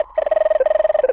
cartoon_electronic_computer_code_01.wav